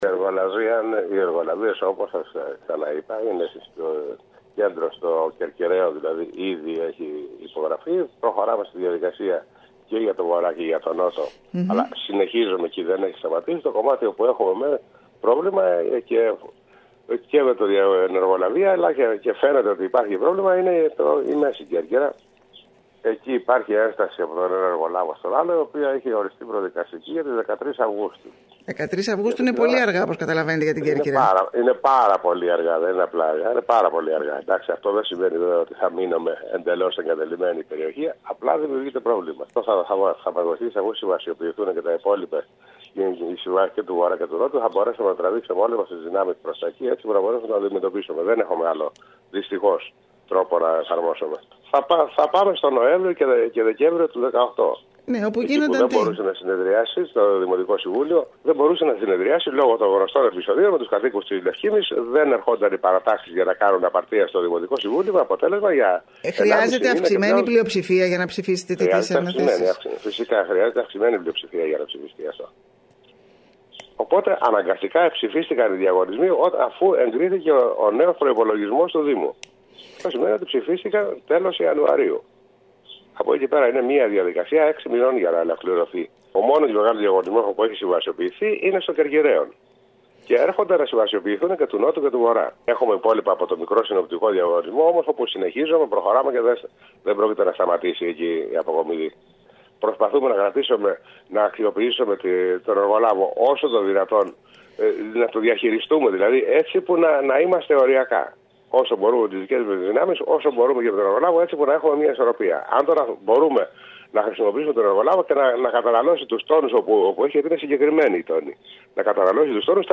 Συνεχίζονται σε πολλές περιοχές της μέσης Κέρκυρας τα προβλήματα με την αποκομιδή των απορριμμάτων με αποτέλεσμα να δημιουργούνται μικρές χωματερές. Μιλώντας στην ΕΡΤ Κέρκυρας ο αντιδήμαρχος καθαριότητας Σπύρος Καλούδης παραδέχτηκε ότι υπάρχει πρόβλημα με τη σύμβαση του εργολάβου για τη μέση Κέρκυρα και εκτίμησε ότι θα γίνει προσπάθεια εξομάλυνσης μετά την υπογραφή συμβάσεων με εργολάβους για βορά και νότο.